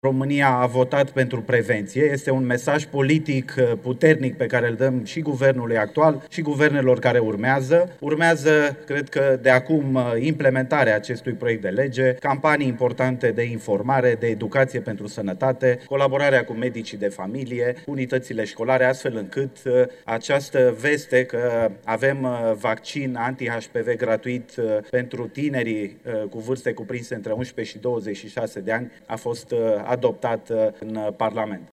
Ovidiu Câmpean, inițiatorul proiectului: „România a votat pentru prevenție”